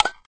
clack_can_opening.ogg